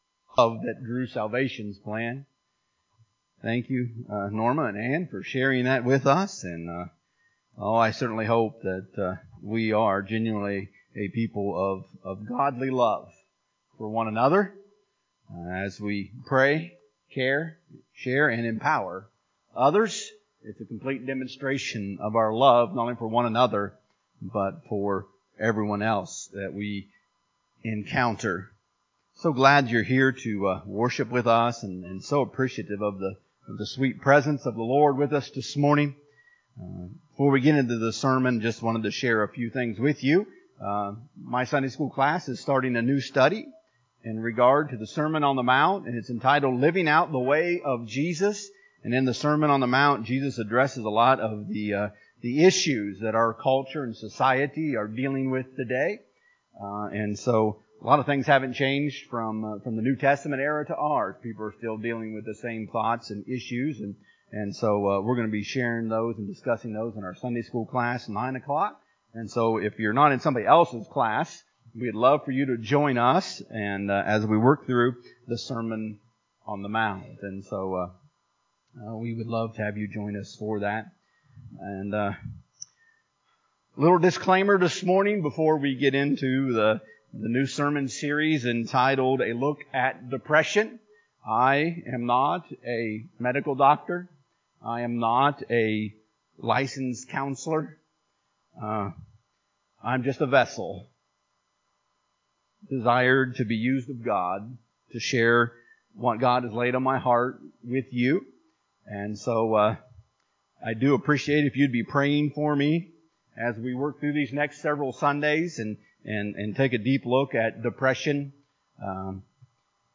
If you would like to watch the recording of the service, please CLICK HERE.